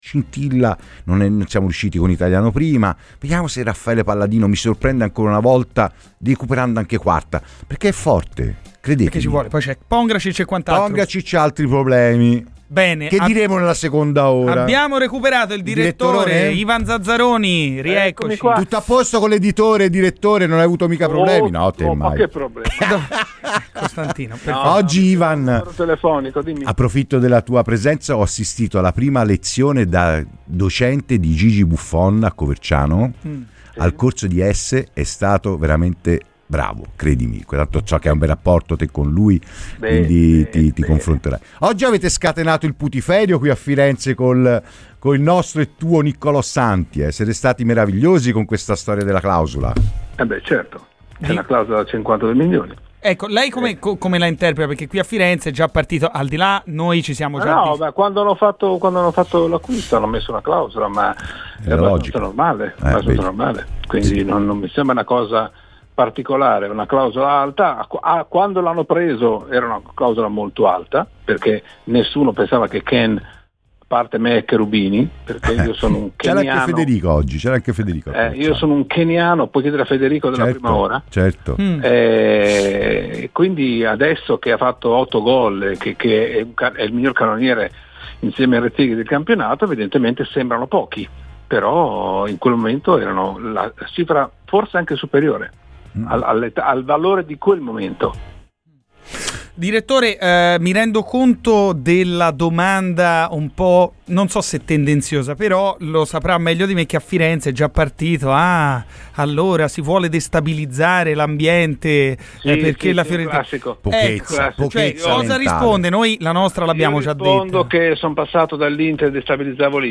Il direttore del Corriere dello Sport-Stadio Ivan Zazzaroni è intervenuto ai microfoni di Radio FirenzeViola durante "Garrisca al Vento".